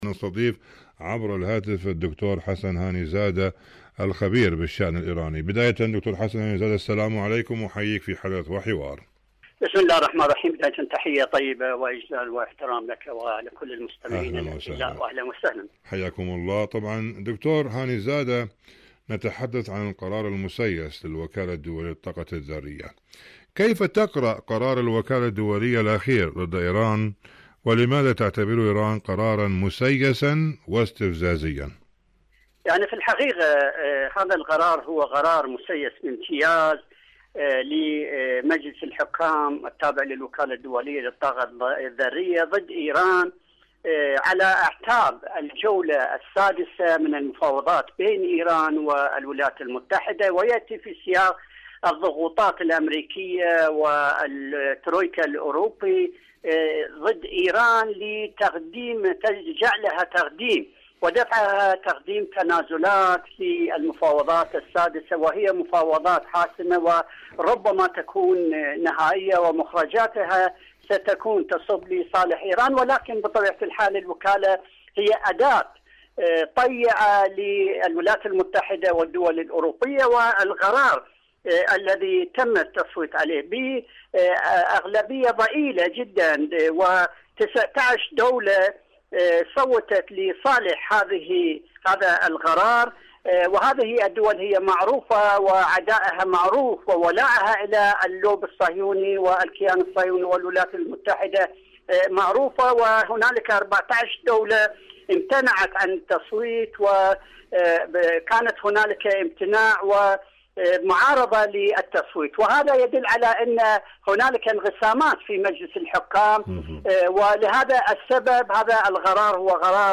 مقابلات برامج إذاعة طهران العربية برنامج حدث وحوار مقابلات إذاعية القرار المسيس للوكالة الدولية للطاقة النووية القرار المسيّس للوكالة الدولية للطاقة الذرية ما سبب إصدار الوكالة الدولية للطاقة الذرية قرارا مسيسا ضد إيران؟